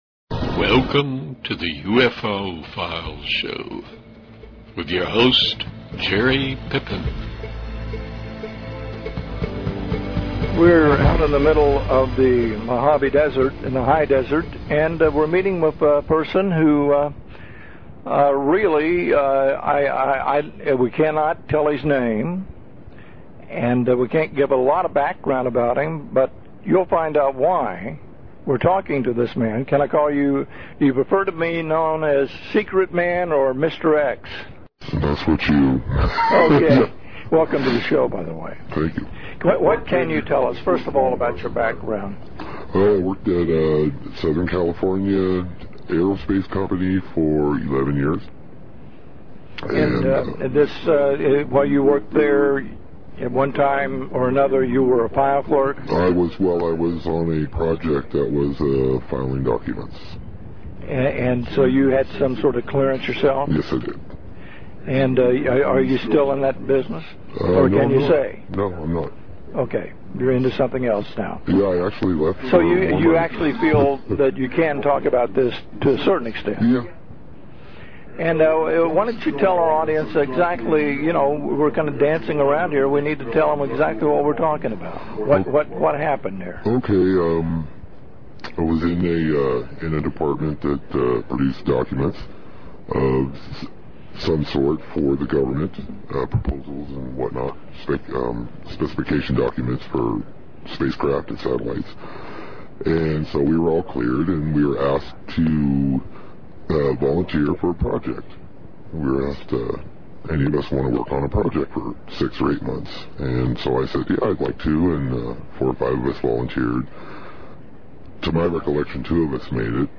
We made some small talk and immediately started rolling tape.
His story was told with sincerity, and he was reeling off fantastic details in a matter of fact way.